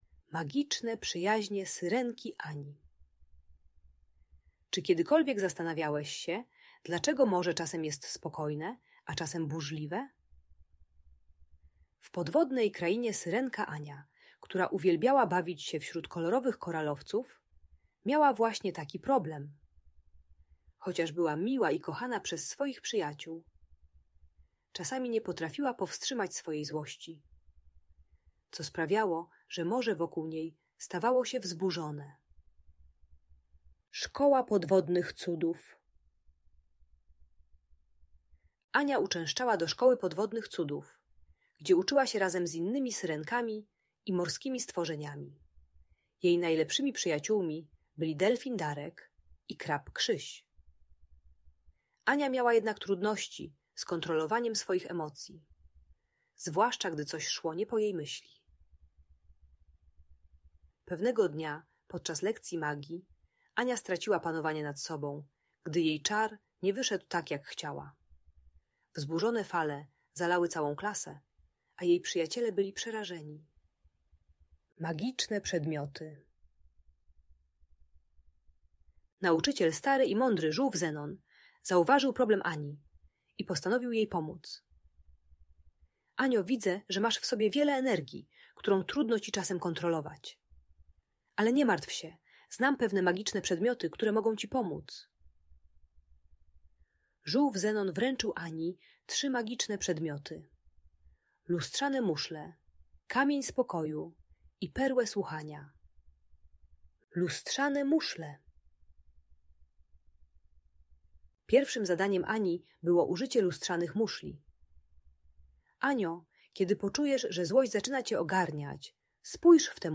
Magiczne Przyjaźnie Syrenki Ani - Bajkowa Opowieść - Audiobajka